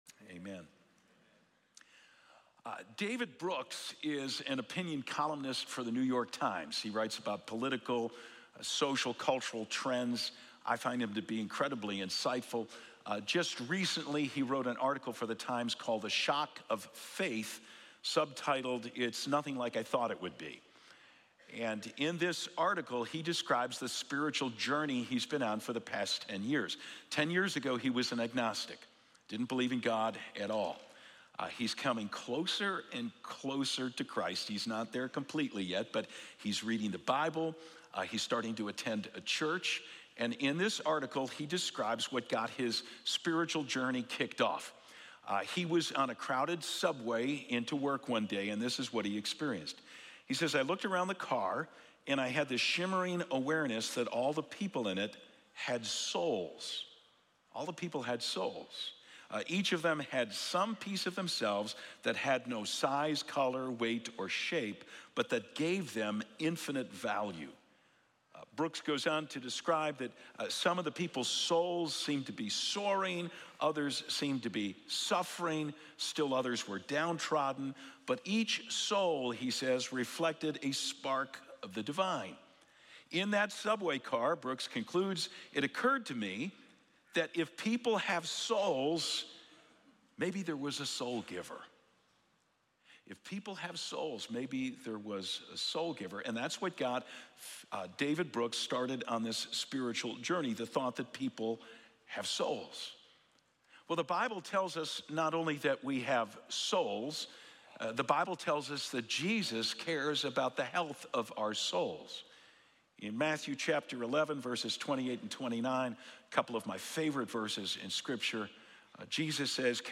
1-13-25-Sermon.mp3